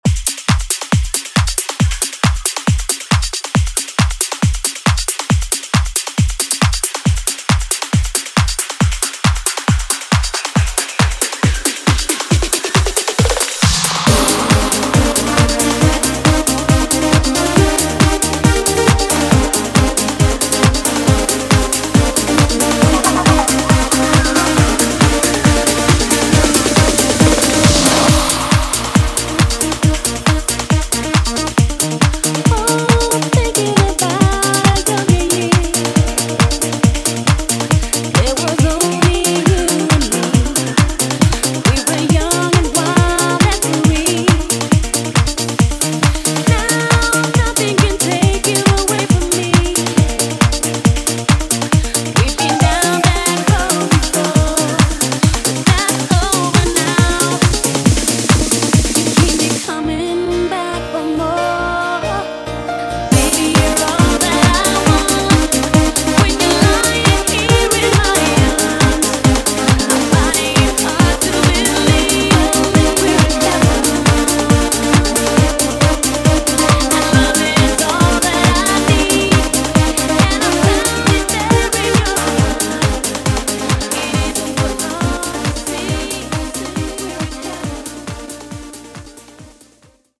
Techno House)Date Added